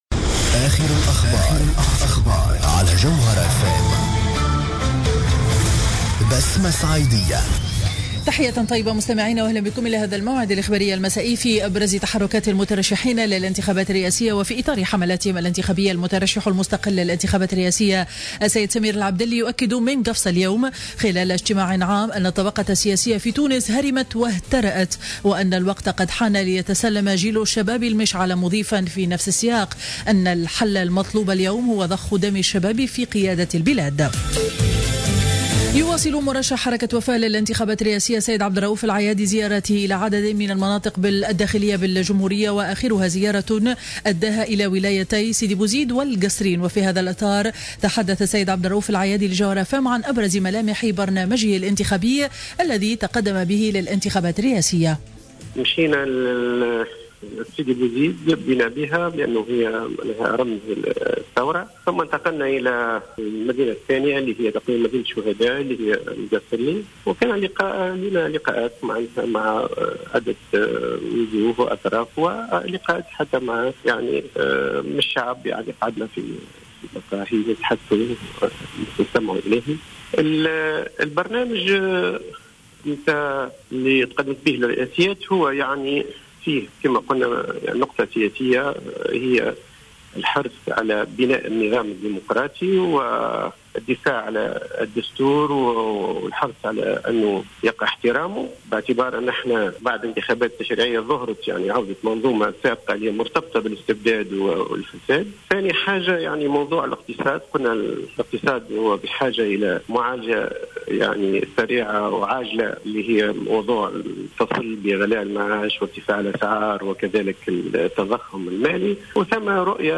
نشرة أخبار السابعة مساء ليوم الثلاثاء 18-11-14